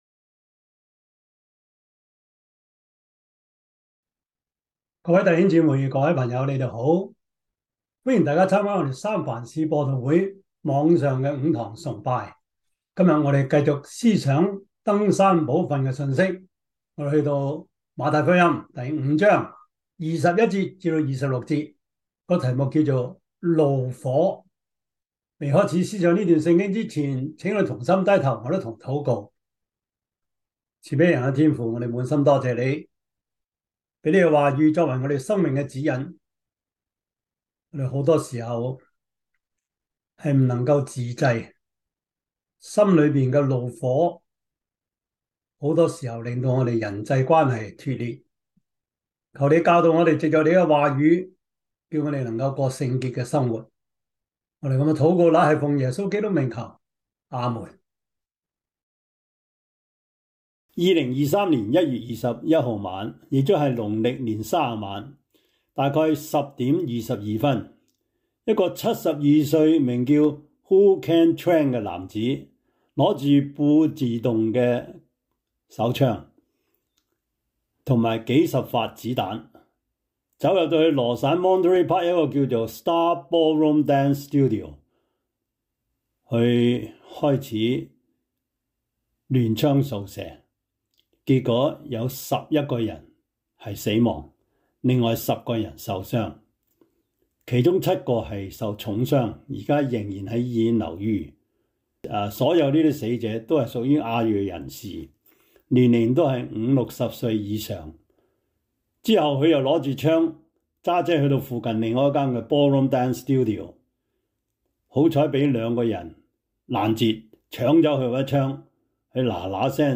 馬太福音 5:21-26 Service Type: 主日崇拜 馬太福音 5:21-26 Chinese Union Version